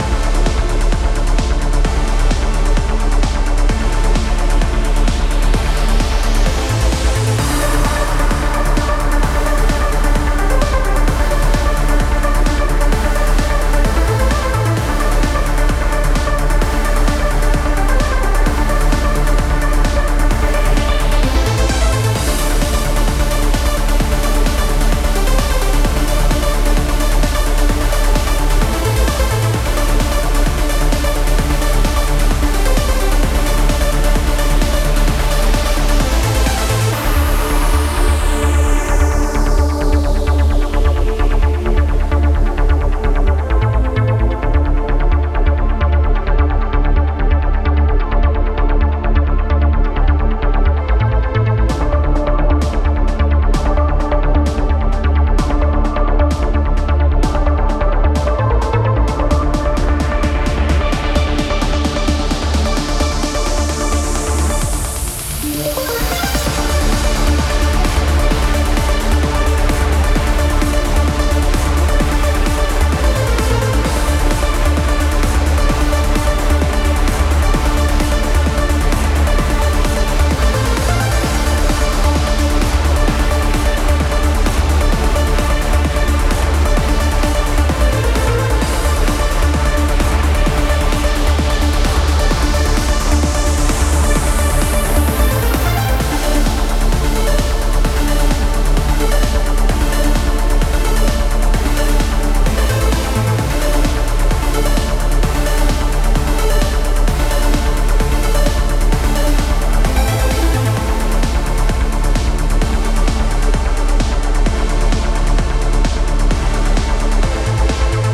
Trance Music for City theme.
trance_city_bpm130_0.ogg